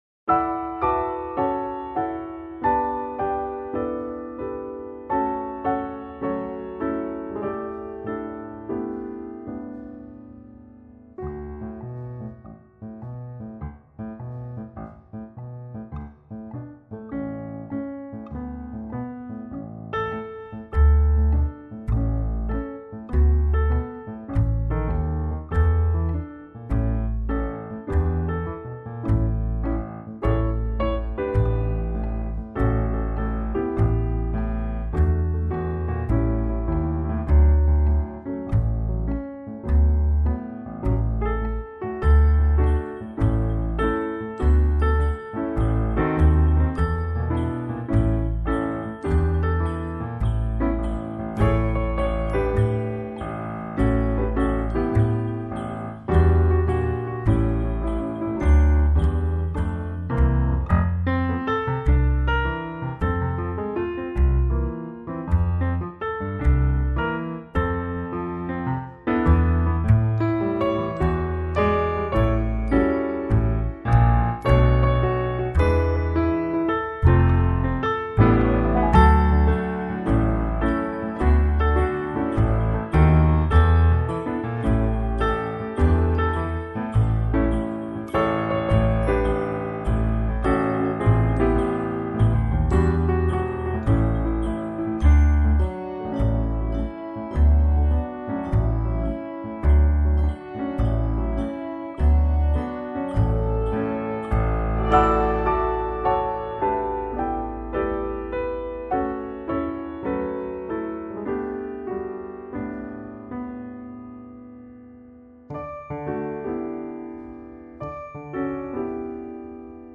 This is very beautiful, so relaxing.